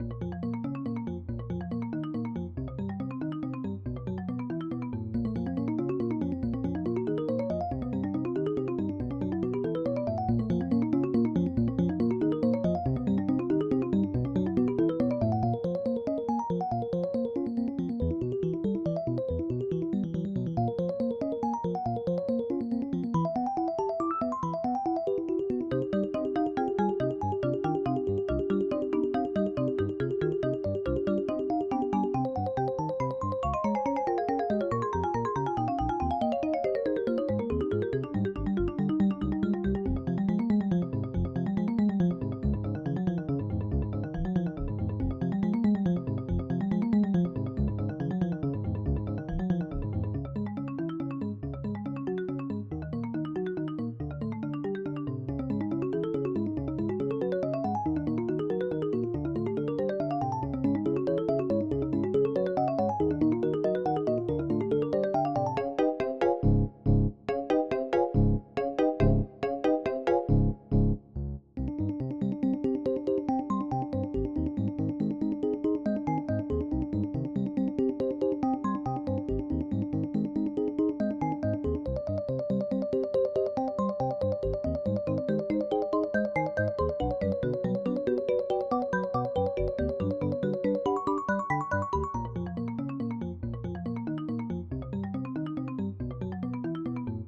Electronic / 2009